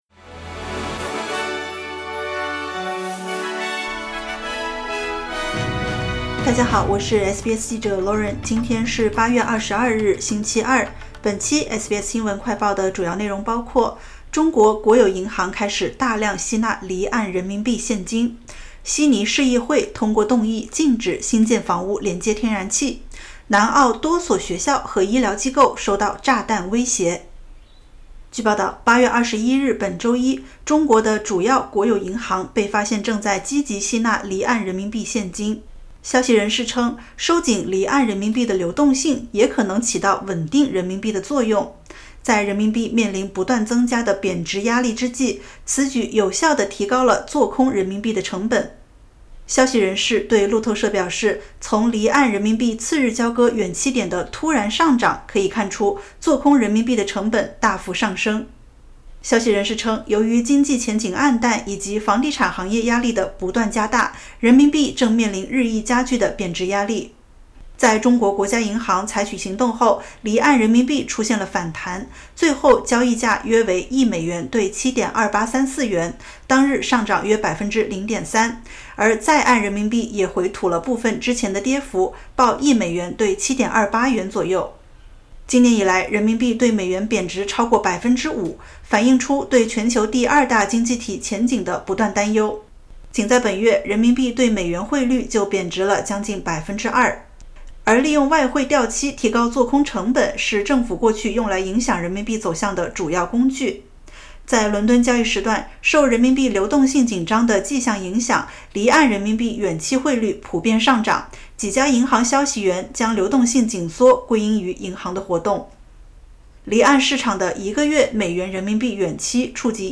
【SBS新闻快报】中国国有银行开始大量吸纳离岸人民币现金